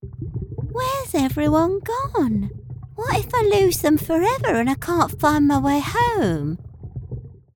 Anglais (sud-africain)
Animation
Micro Audio Technica AT2020